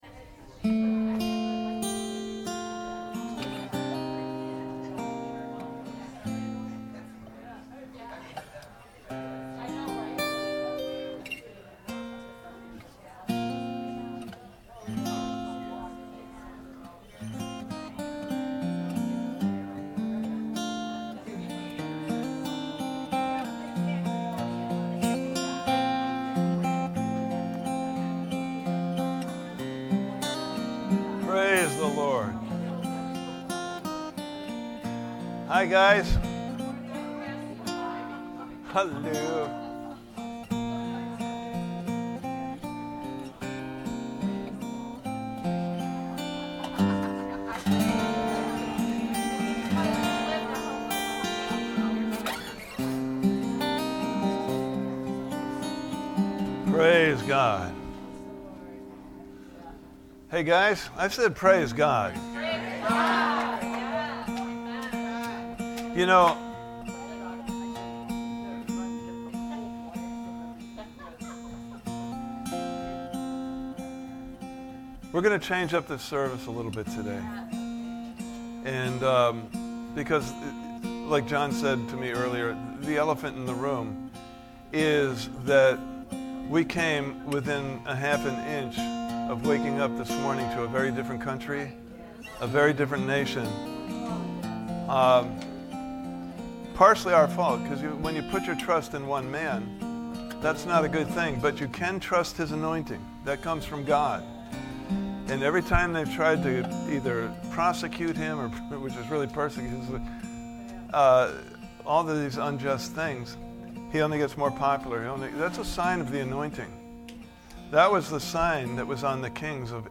various Service Type: Sunday Morning Service « Part 1